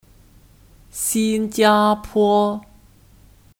新加坡 Xīnjiāpō (Kata benda): Singapura